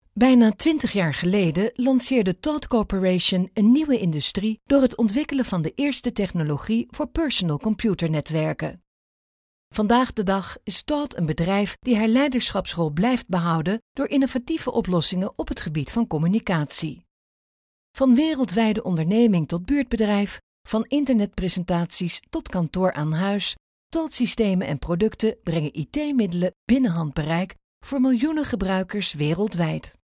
Dutch female voice over
Sprechprobe: Industrie (Muttersprache):